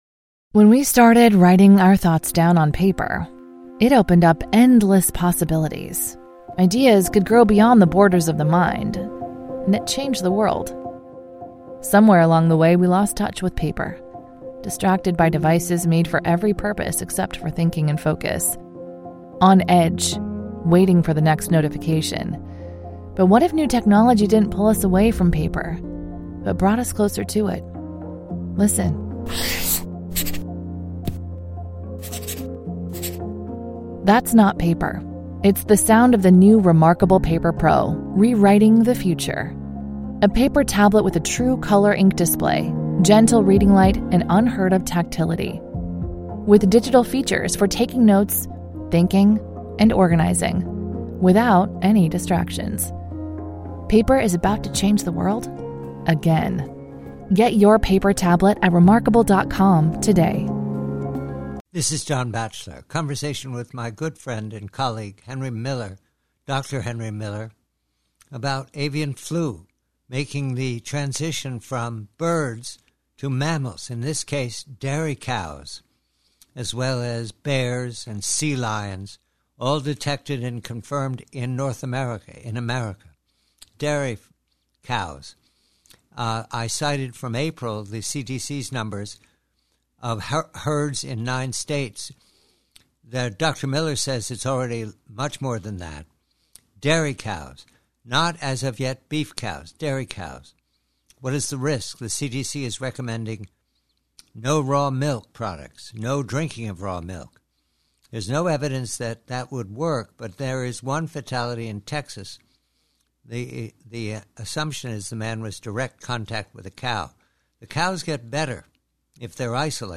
PREVIEW: AVIAN FLU: Conversation with Dr,.